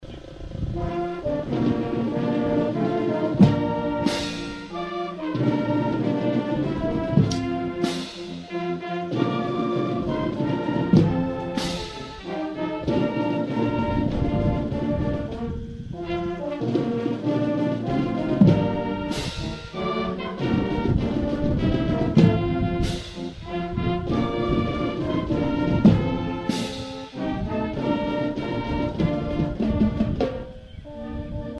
Service Men and Women Honored at Memorial Day Service at the Atlantic Cemetery
The Atlantic Boy Scout Troop #54 led off the ceremony with the presentation of the American Flag, followed by the playing of our National Anthem by the Atlantic High School Band at the Atlantic cemetery this morning.